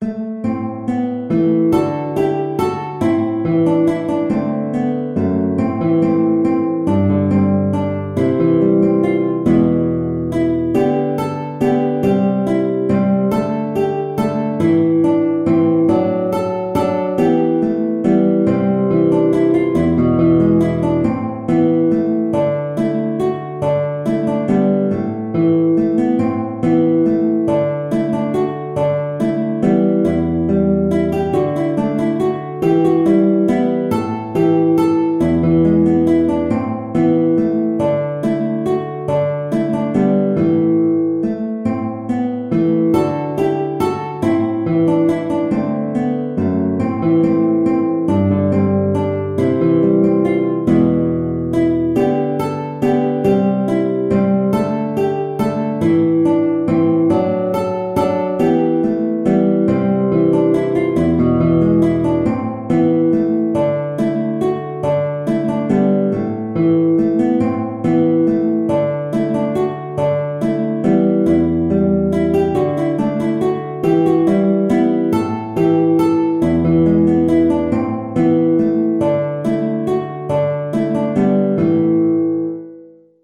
Free Sheet music for Guitar
E minor (Sounding Pitch) (View more E minor Music for Guitar )
3/4 (View more 3/4 Music)
E3-A5
Guitar  (View more Easy Guitar Music)
Traditional (View more Traditional Guitar Music)
All_Things_Are_Quite_Silent_GTR.mp3